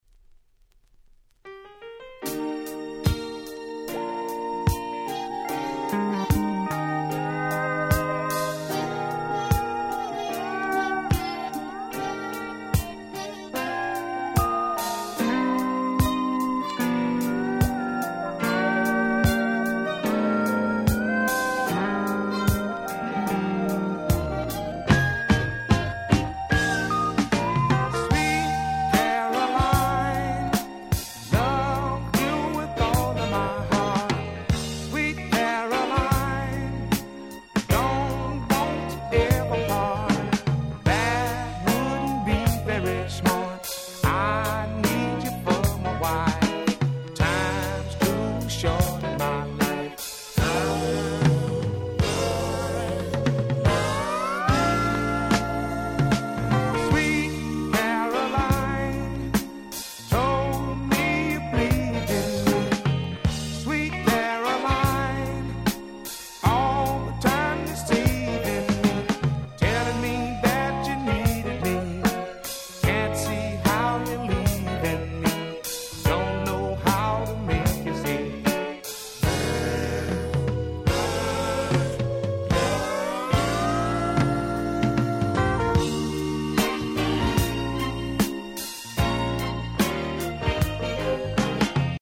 80' Nice Funk/Soul !!